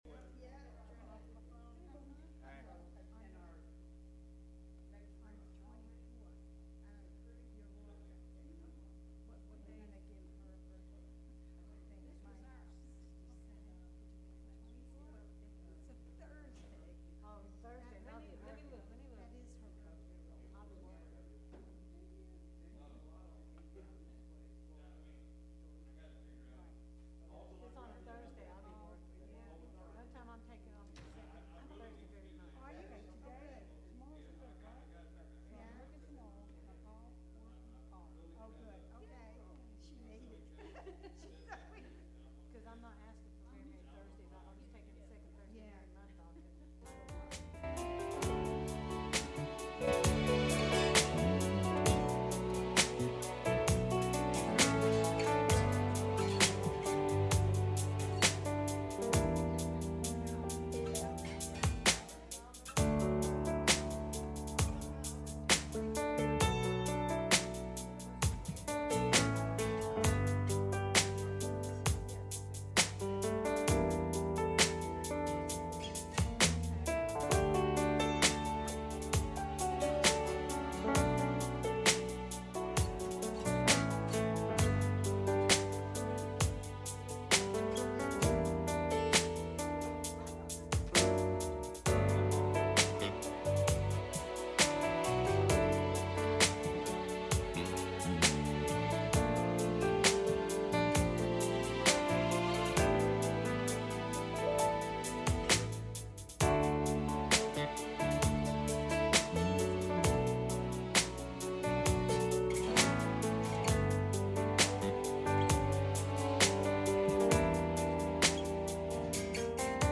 John 21:1-6 Service Type: Midweek Meeting « Run Home